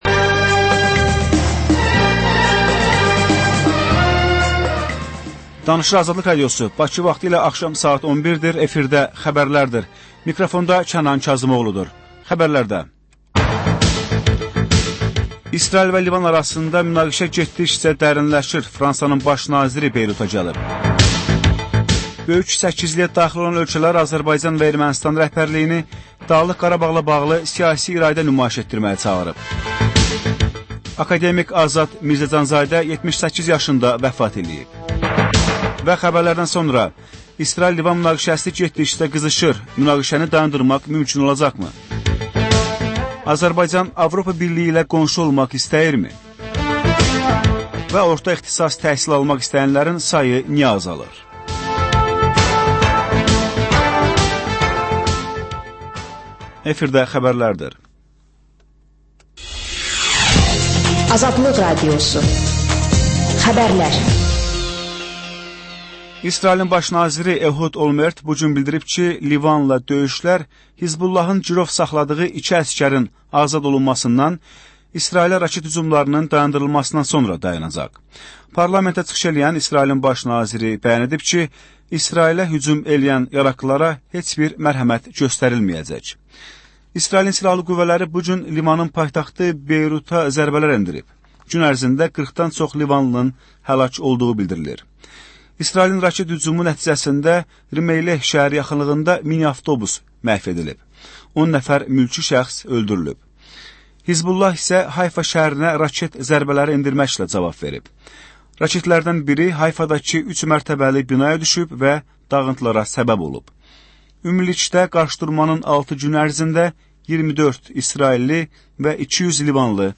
Xəbərlər, reportajlar, müsahibələr. Hadisələrin müzakirəsi, təhlillər, xüsusi reportajlar.